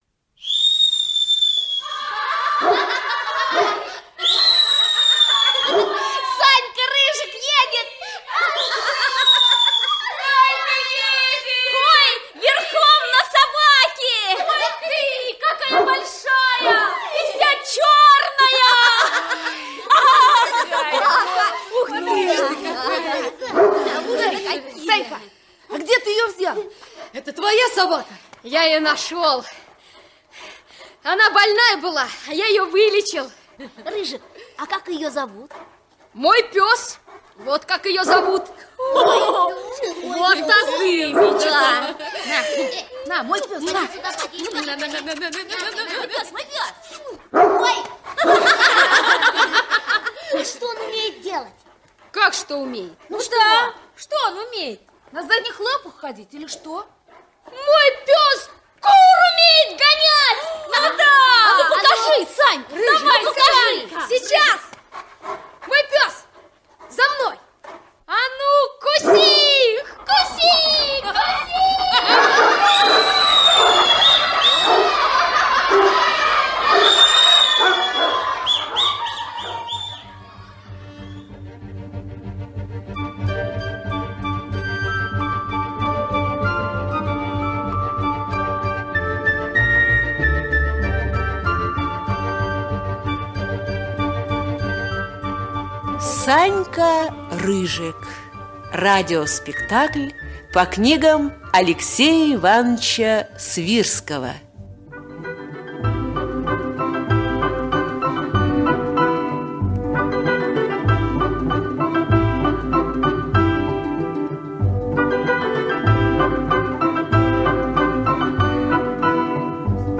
На данной странице вы можете слушать онлайн бесплатно и скачать аудиокнигу "Рыжик" писателя Алексей Свирский. Включайте аудиосказку и прослушивайте её на сайте в хорошем качестве.